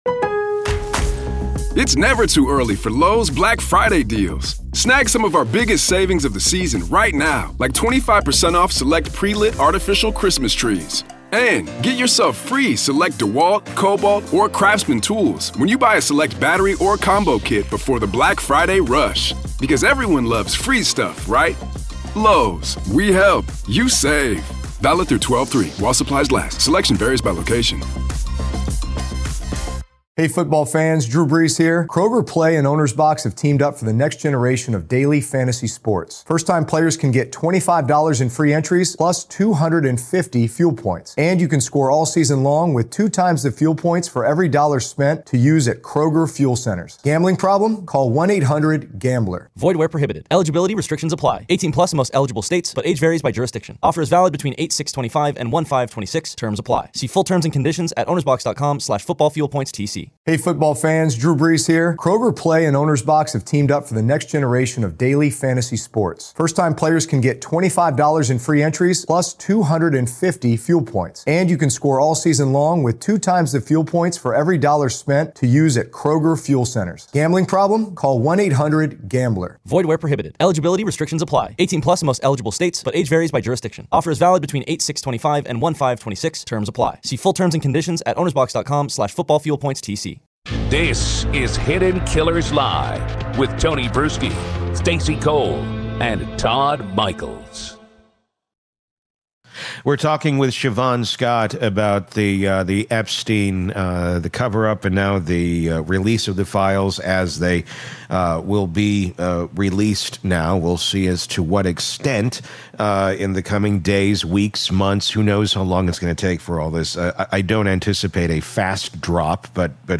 In this gripping conversation
psychotherapist